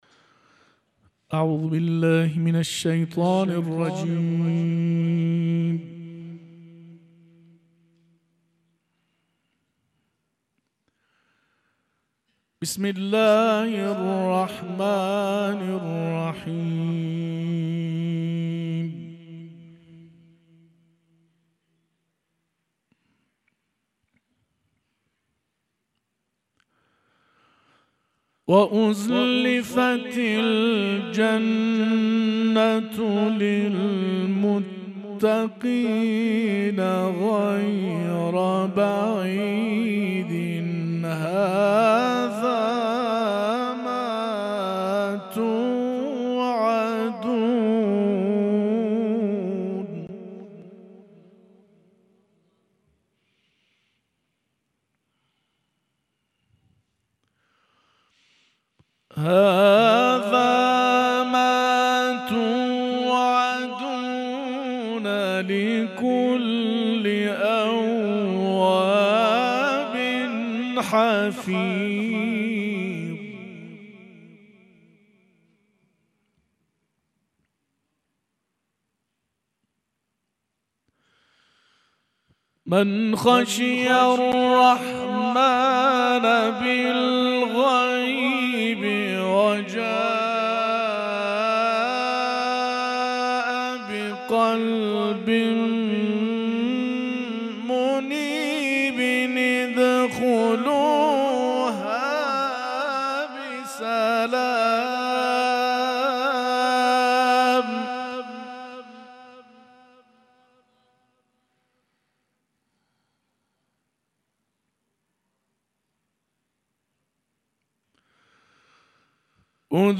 به گزارش خبرگزاری بین‌المللی قرآن(ایکنا)، به مناسبت هفته قرآن، کانون قرآن و عترت دانشگاه علم و صنعت، از روز شنبه 20 آذر پس از اقامه نماز ظهر و عصر، اقدام به برگزاری محافل قرآنی نموده است.
قبل از تلاوت، دقایقی به سخنرانی درباره ضرورت قرآن خواندن و تلاوت قرآن پرداخت و سپس آیاتی از سوره مبارکه ق را تلاوت کرد